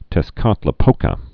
(tĕs-kätlĭ-pōkä)